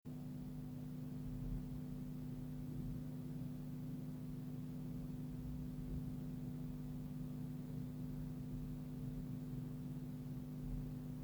Гудят активные мониторы M-audio BX8a
Очень тихо, но фонят.